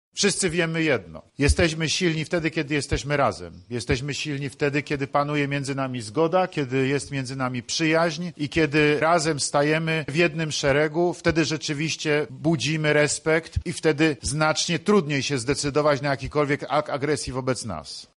O współpracy i o tym co można tym osiągnąć, mówił Prezydent Andrzej Duda.